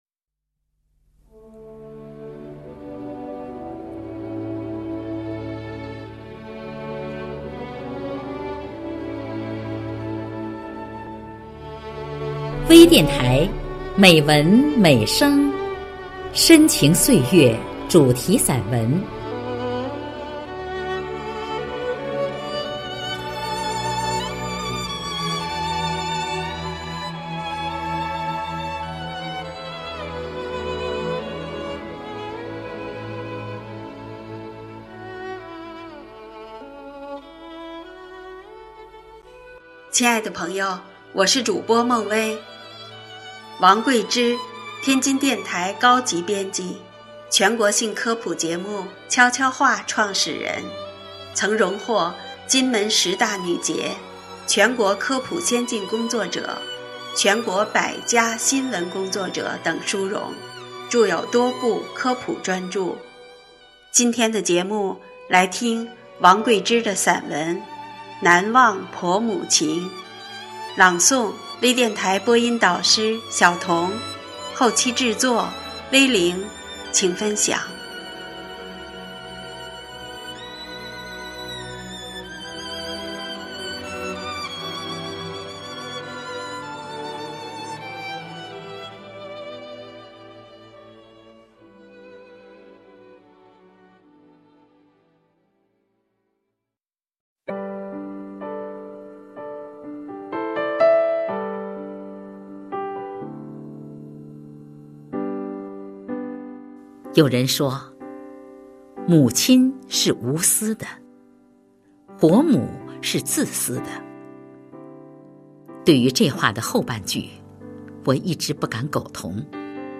朗诵
专业诵读 精良制作